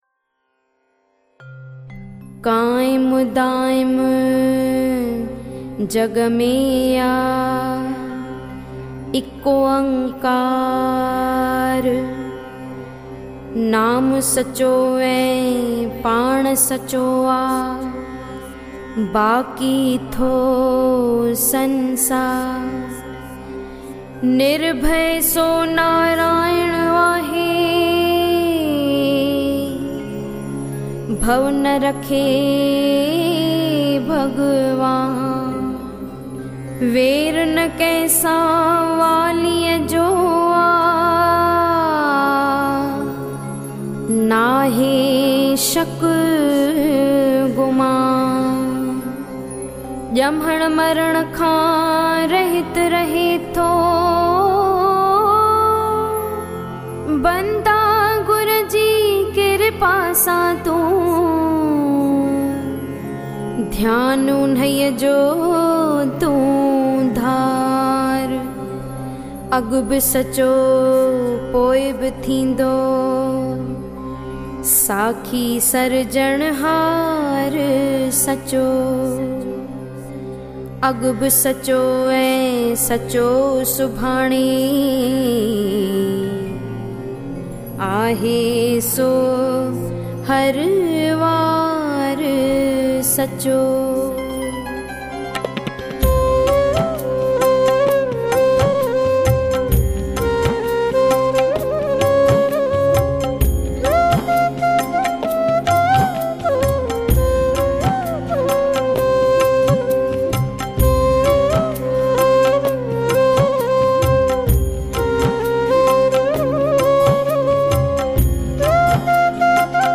In Melodious Voice